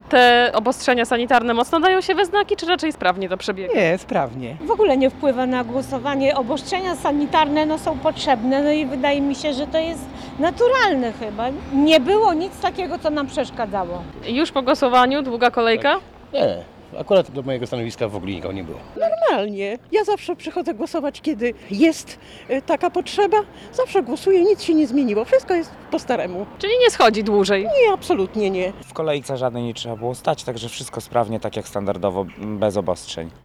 Po godzinie 13:00 nasza reporterka była pod komisjami wyborczymi przy Ełckim Centrum Kultury i Szkole Podstawowej nr 2. Ełczanie, z którymi rozmawiała podkreślali, że głosowanie odbywa się sprawnie, a stosowanie się do dodatkowych obostrzeń nie sprawia im większych trudności.